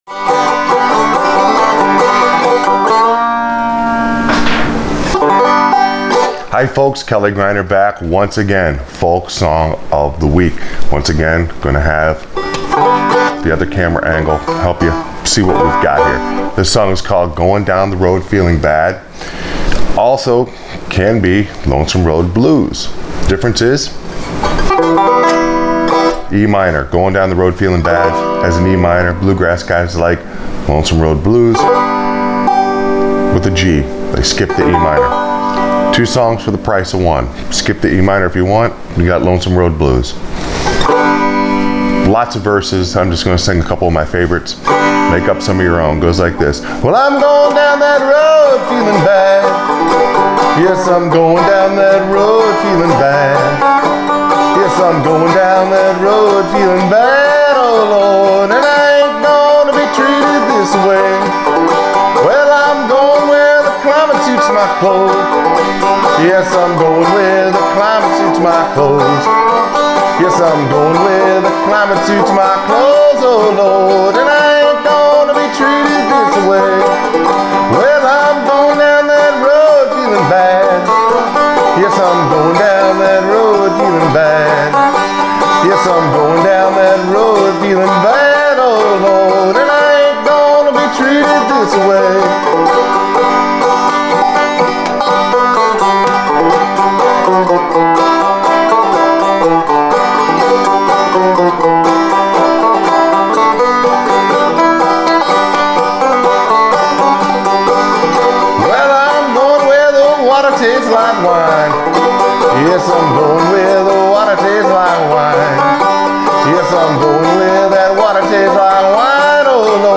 Folk Song Of The Week – Going Down The Road Feeling Bad on Frailing Banjo